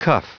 Prononciation du mot cuff en anglais (fichier audio)
Prononciation du mot : cuff